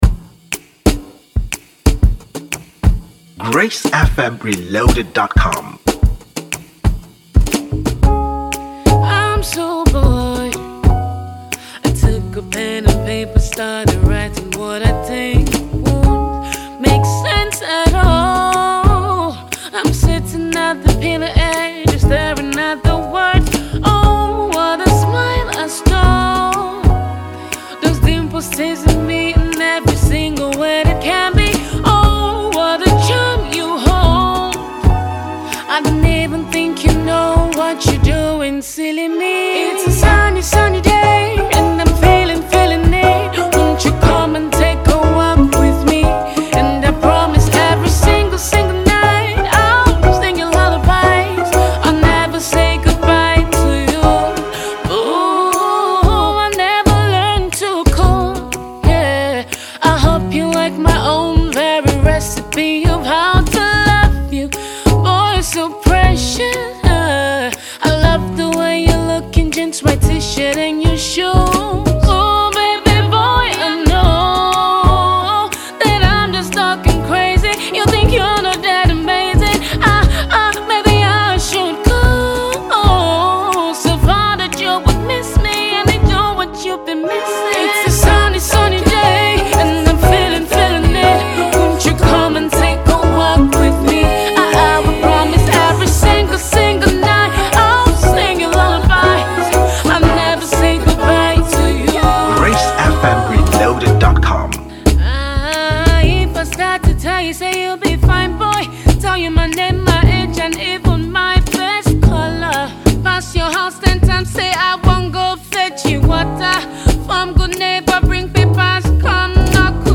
afrocentric
was recorded, mixed and mastered at the Lokoja studios